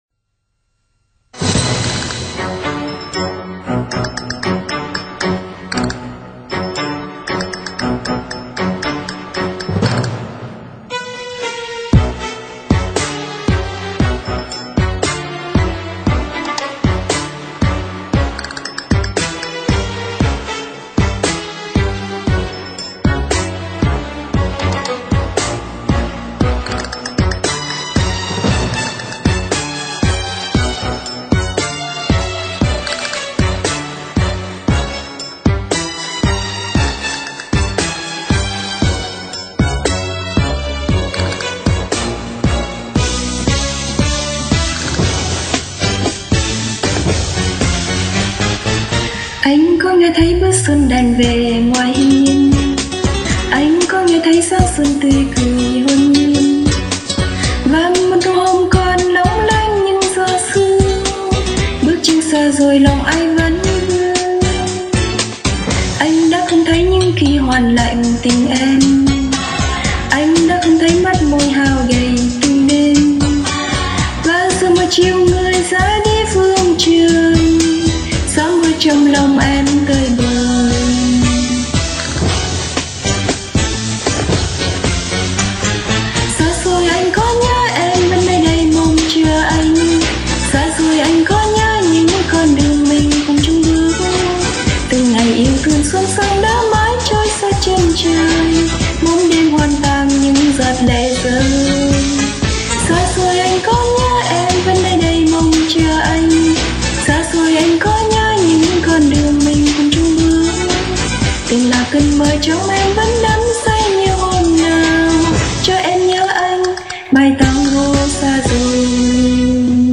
nhịp nhàng tha thướt theo điệu nhạc nhacvn.gif eusa_clap.gif